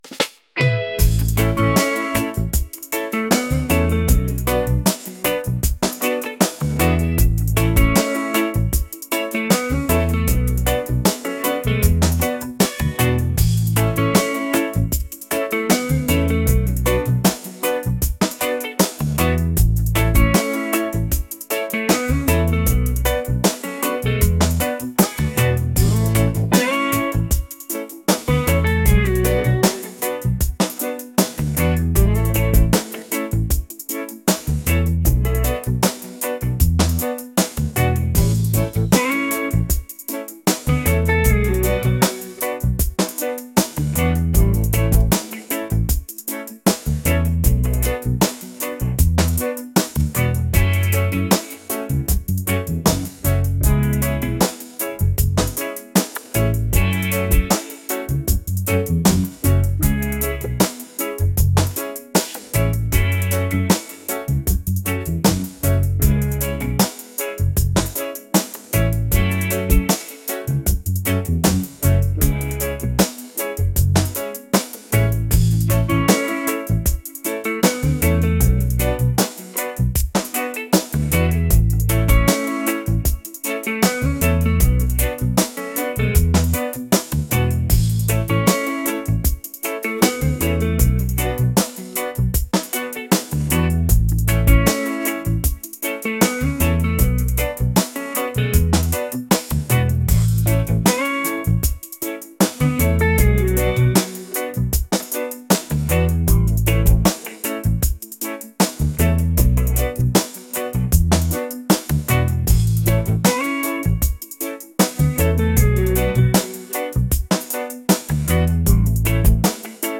reggae | smooth | laid-back